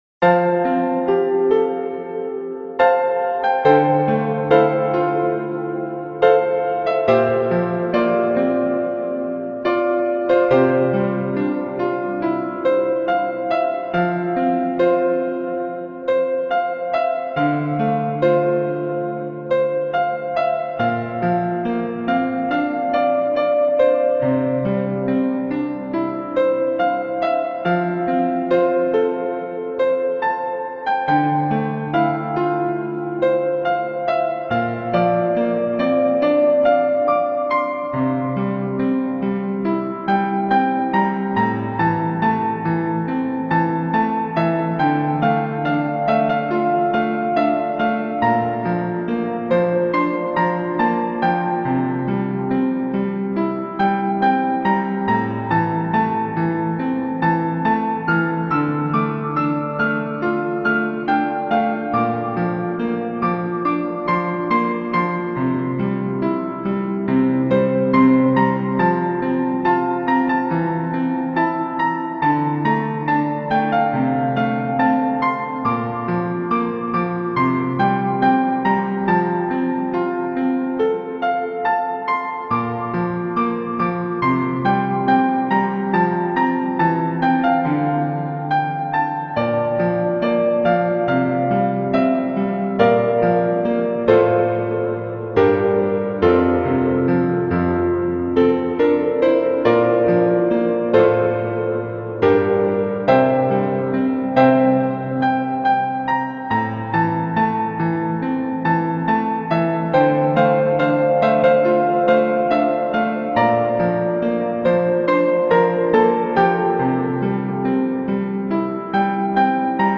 TongHua_piano.mp3